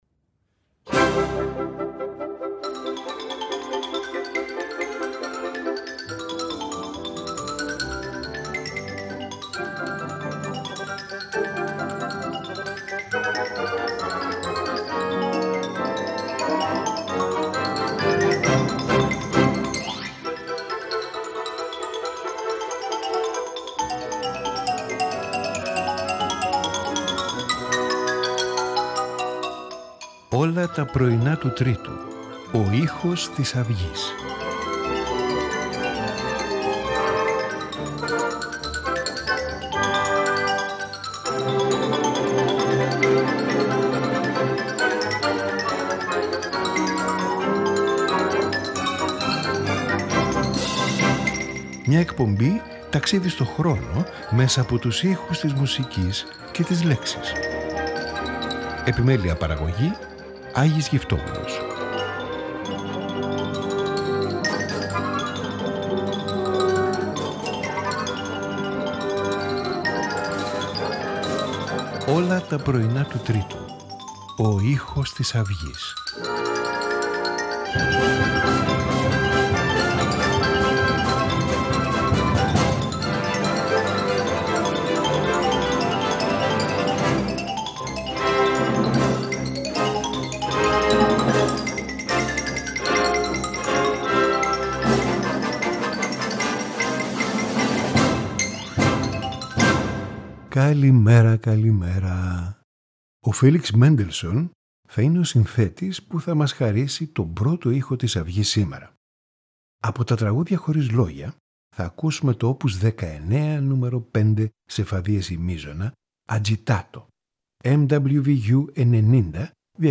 Piano Sonata No 14 in A minor
Cello Concerto No 1